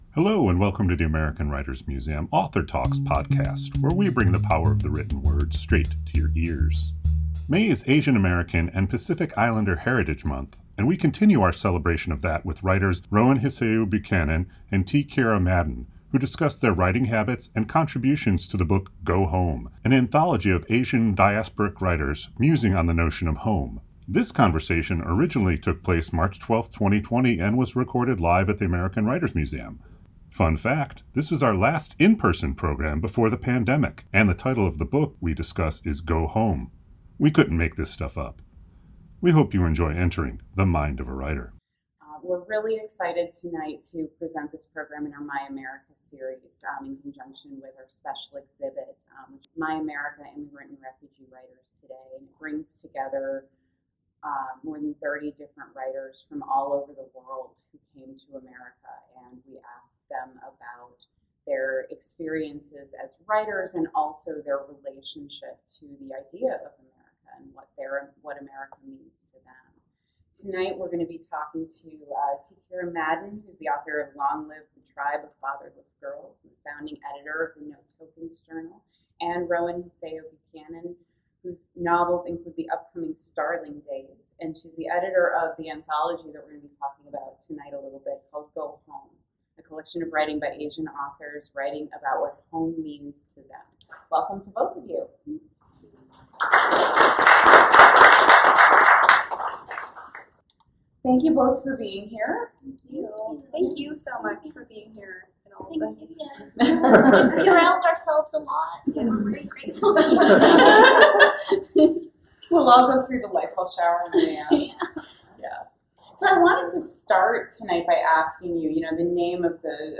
This conversation originally took place March [...]